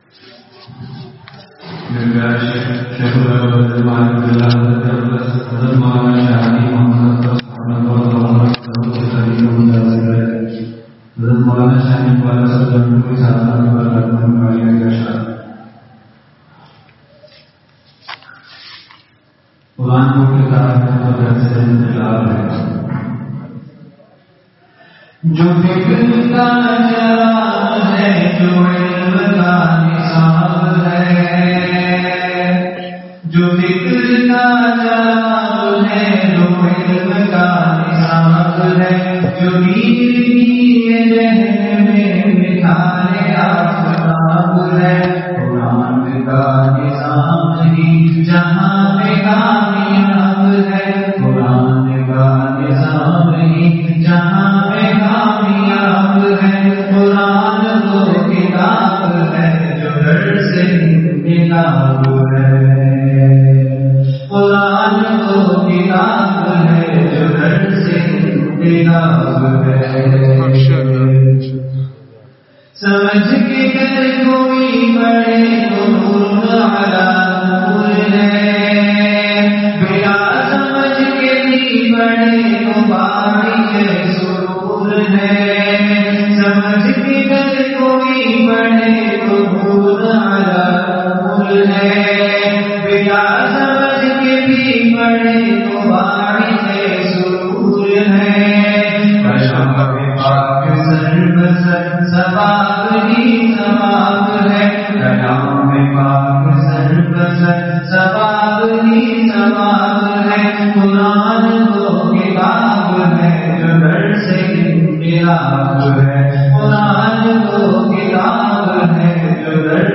Bayan at Shah Faisal Masjid, Nawabshah